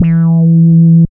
71.07 BASS.wav